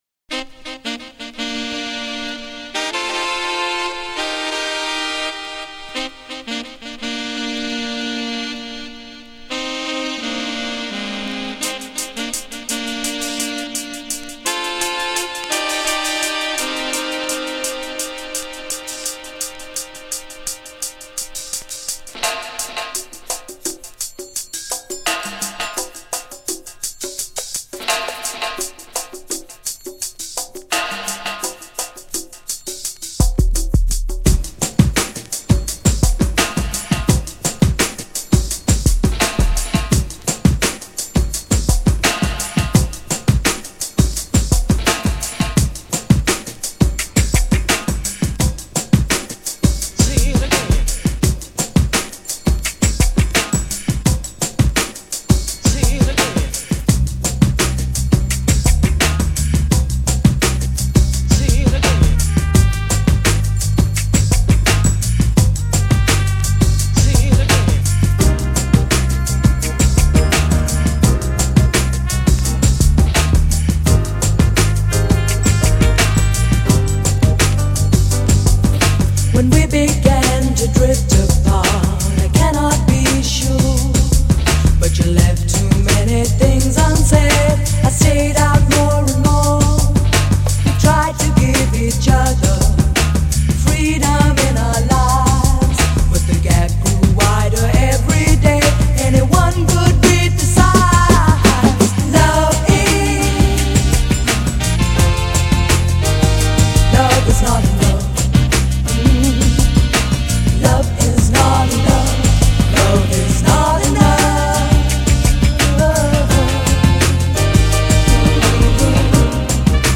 GENRE House
BPM 86〜90BPM
# SAX # アンビエント # グランドビート # ダウンテンポ # ダビー # レゲエ # 妖艶